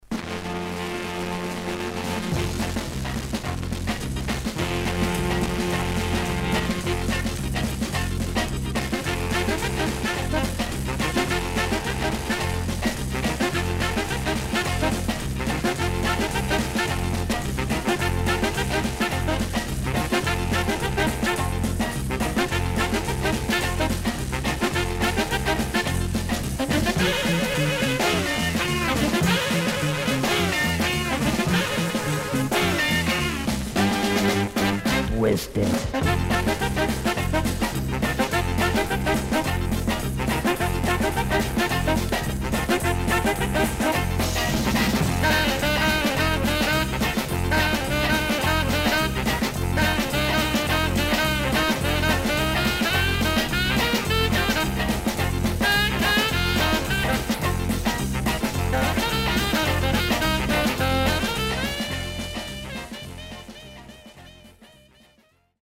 VG+〜VG++ 少々軽いパチノイズの箇所あり。